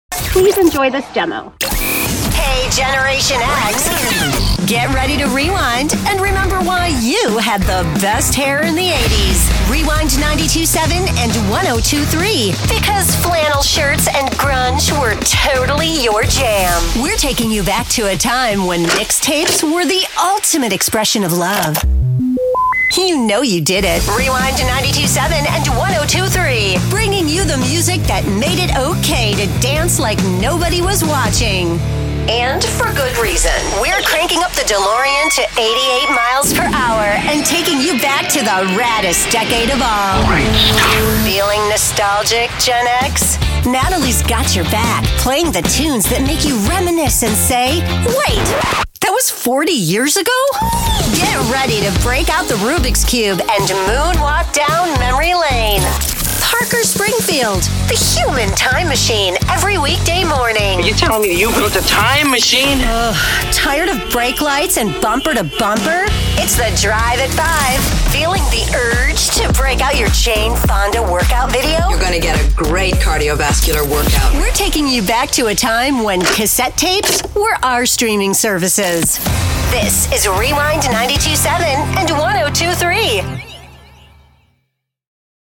Young Adult
Middle Aged